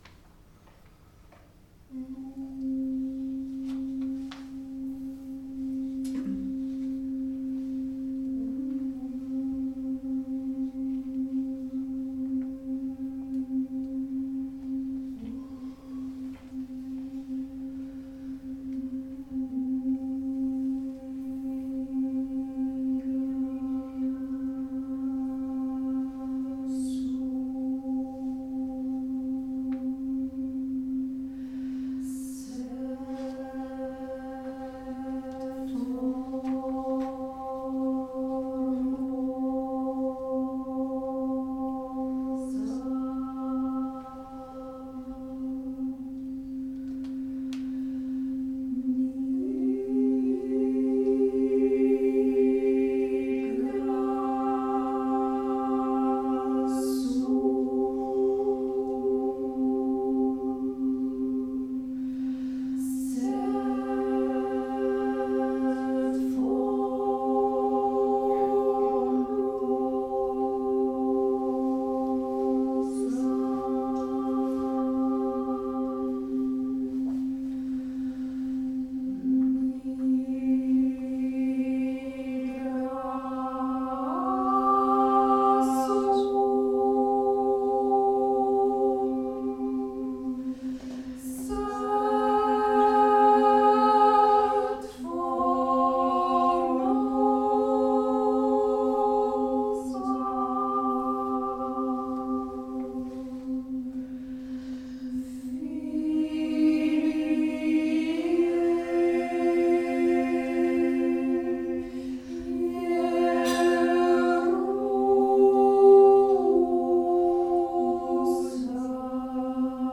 Europese muziek uit de vroege Middeleeuwen en vroege Renaissance. In dit a capella-ensemble ligt de nadruk op vroege polyfonie.
Nigra sum, Gregoriaans antifoon uit het Hooglied, 10e eeuw.